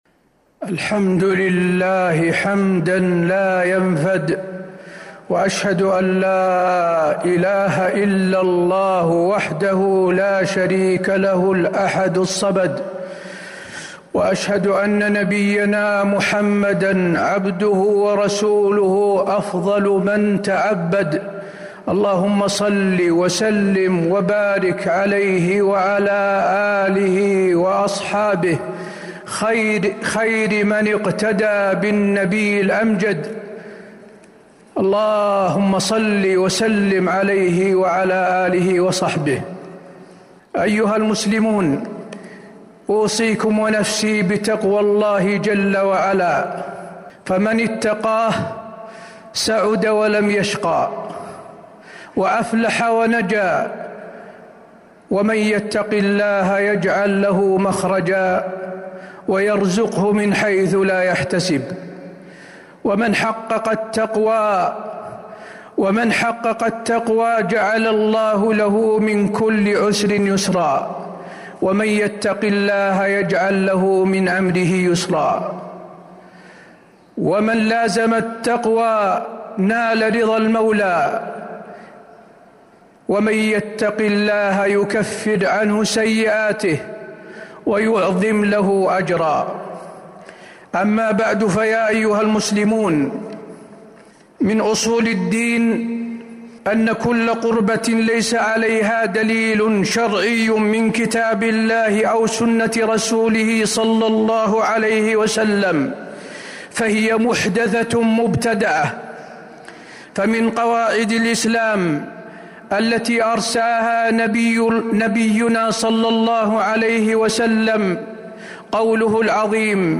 المدينة: شهر رجب والأشهر الحرم - حسين بن عبد العزيز آل الشيخ (صوت - جودة عالية